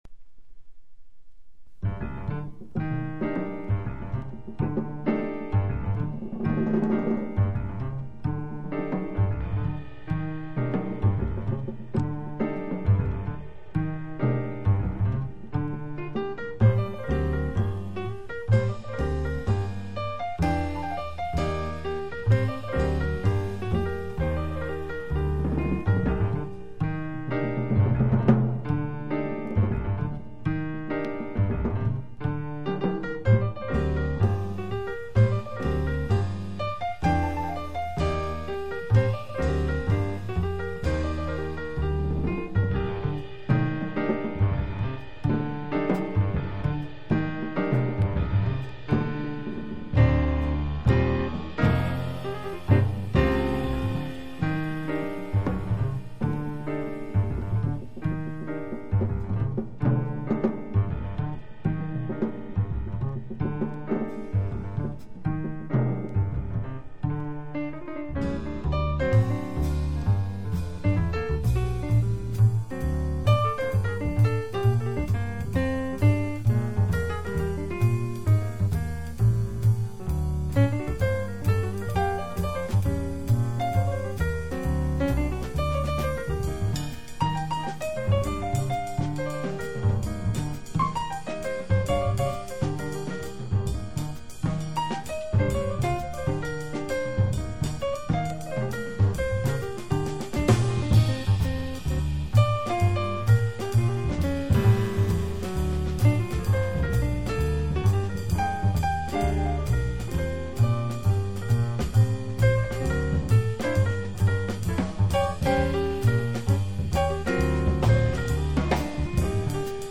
（高音質オーディオファイル）